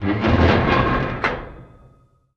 metal_scrape_deep_grind_squeak_06.wav